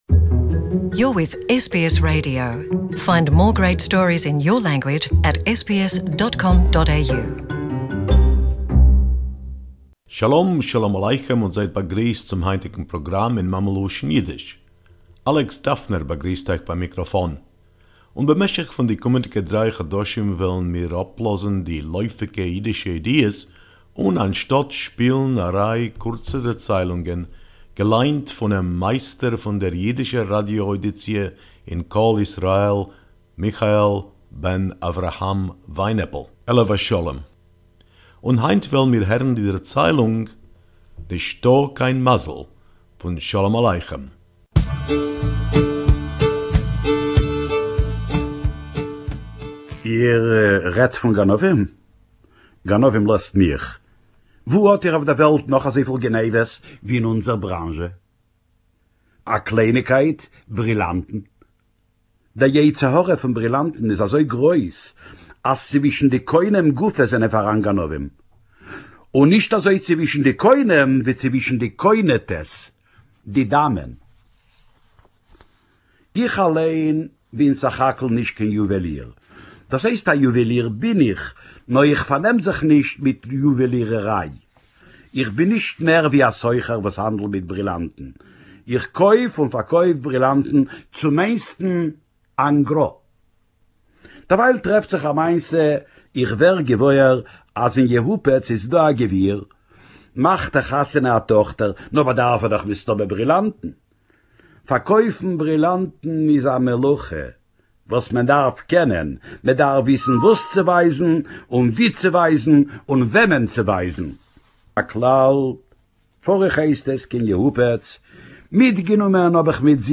Sholem Aleichem Story told in Yiddish